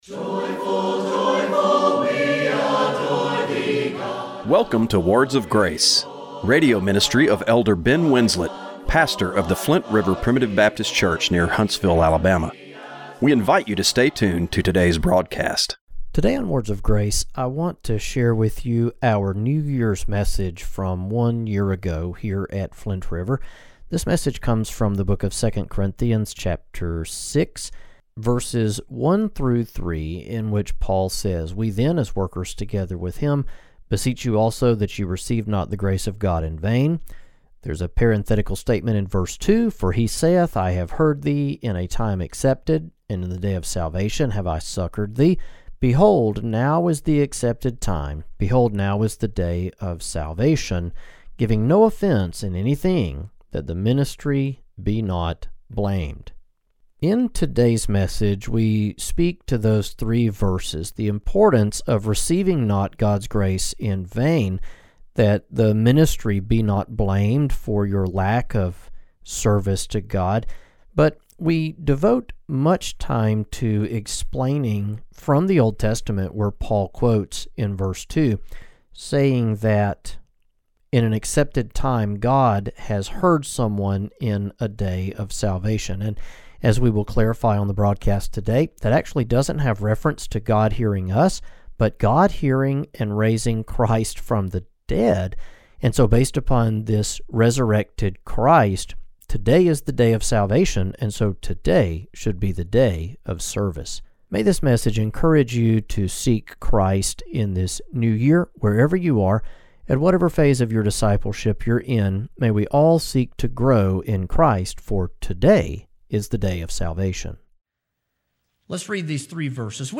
Radio broadcast for December 29, 2024.